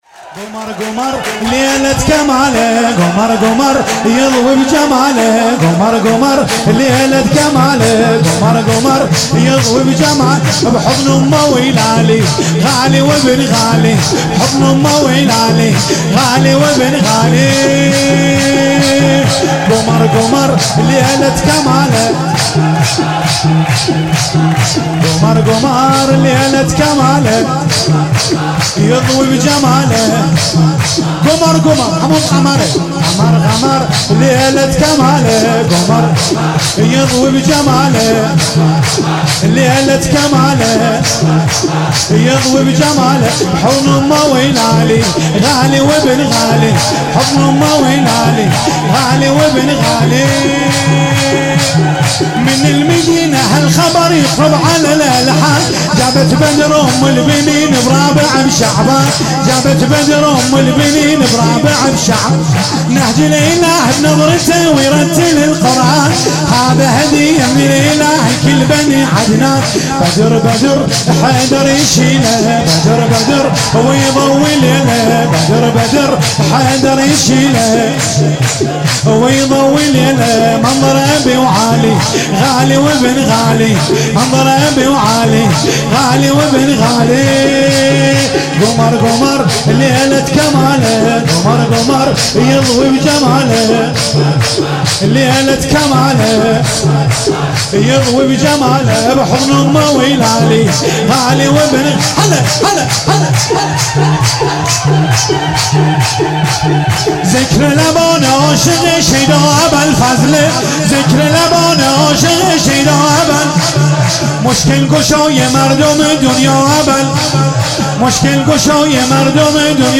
31 فروردین 97 - هیئت محبان الائمه - شور - قمر قمر ليلت کمال